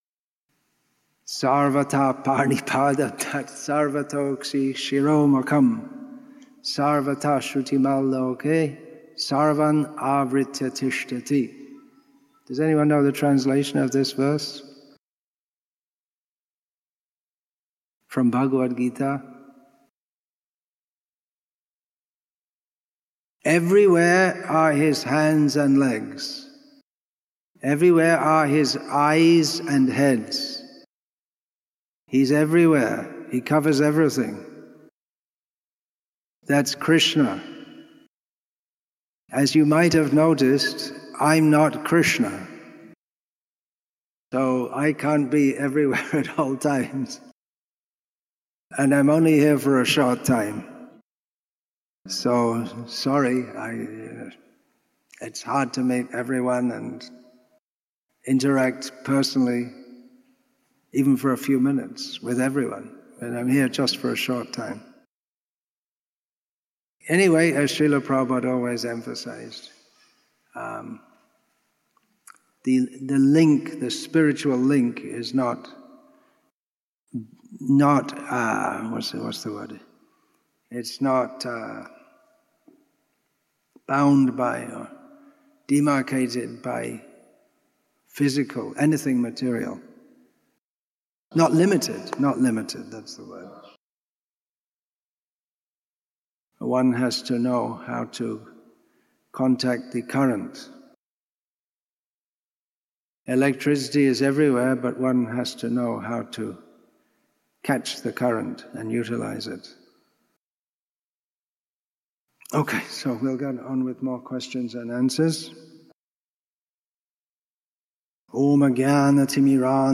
Questions And Answers Session, Part 5